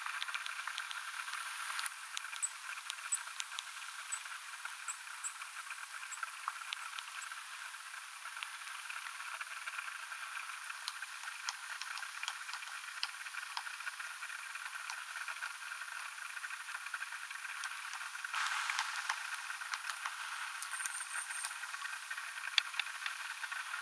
työskentelyäänistä, naputuksista ja koputuksista.
pohjantikan työskentelyääniä, koputuksia
tuollaista_pohjantikan_tyoskentelya.mp3